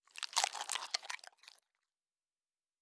pgs/Assets/Audio/Fantasy Interface Sounds/Food Eat 05.wav at master
Food Eat 05.wav